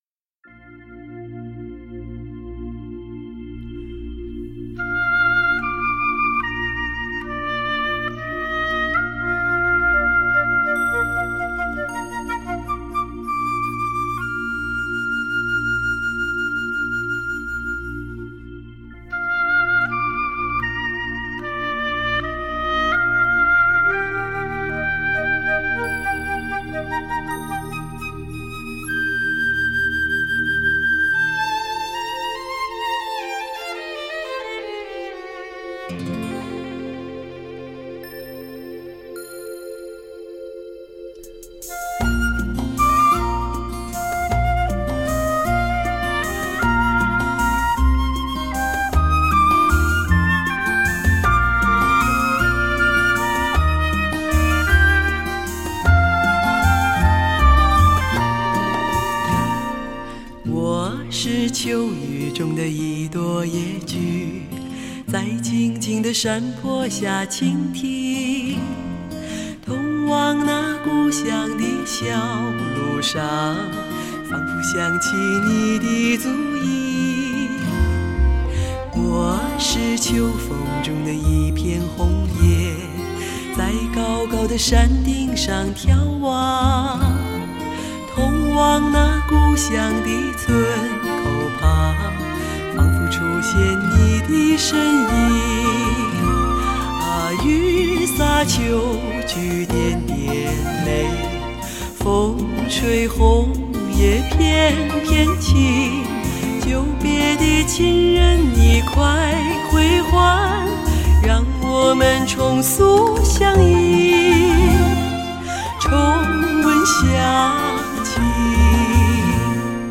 很久没能听到这么醇厚浑厚、圆润柔美的女中音专辑了，
专辑演唱声音基础均衡、编曲高雅大方、活泼、富有生活气息。
音域宽阔，配器编曲音乐动人，风格多样，演唱声音穿透力强。音响结构严密、层次多样而丰富。